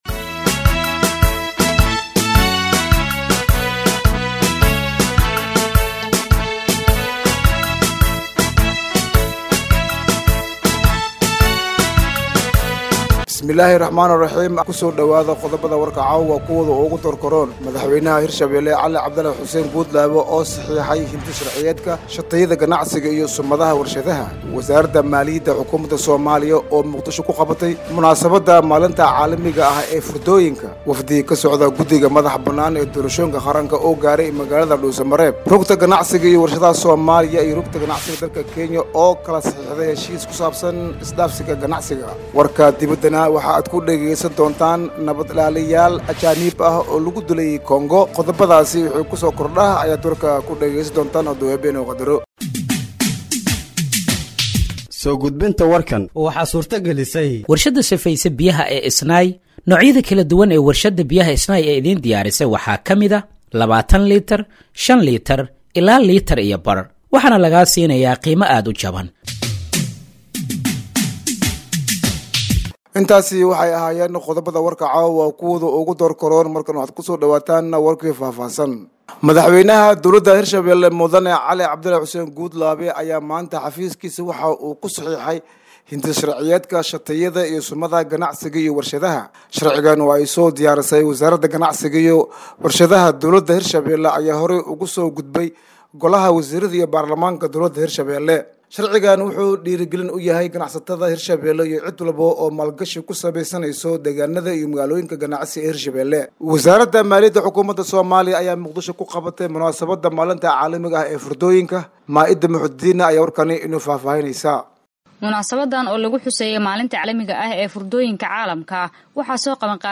Dhageeyso Warka Habeenimo ee Radiojowhar 26/01/2025